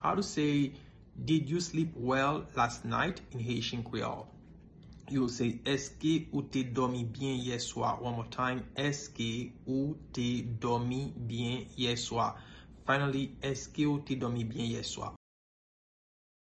Pronunciation:
Did-you-sleep-well-last-night-in-Haitian-Creole-Eske-ou-te-domi-byen-ye-swa-pronunciation.mp3